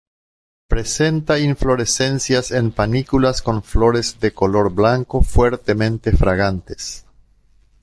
Phát âm là (IPA)
/ˈfloɾes/